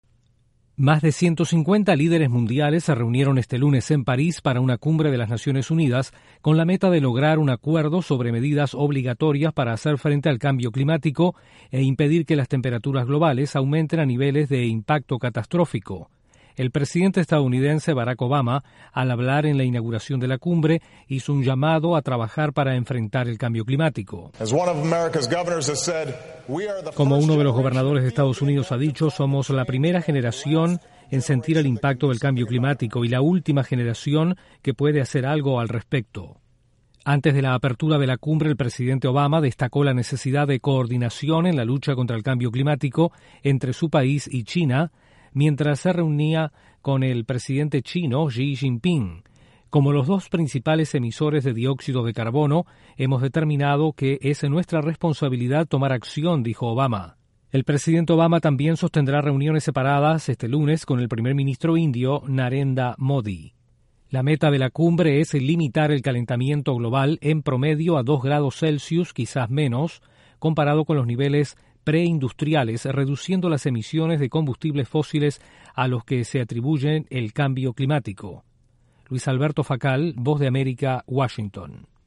El presidente estadounidense, Barack Obama, llama a luchar contra el cambio climático en la inauguración de la cumbre en París. Desde la Voz de América en Washington informa